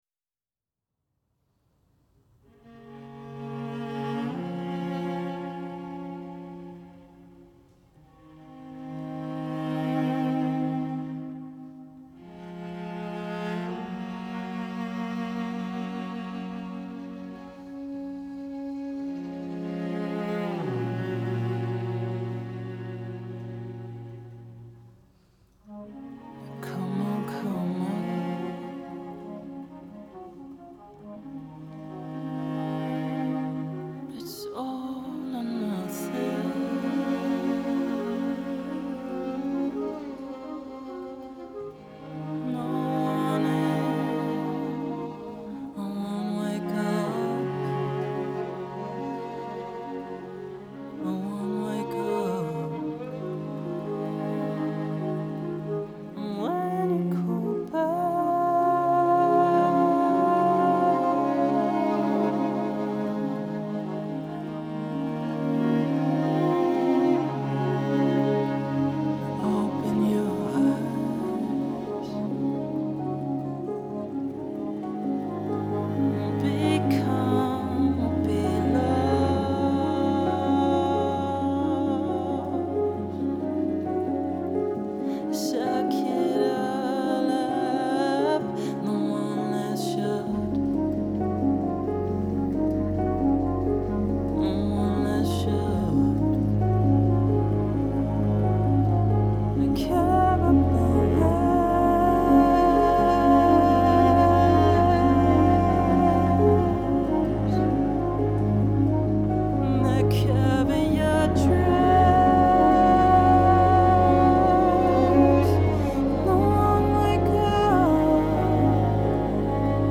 Genre : Classical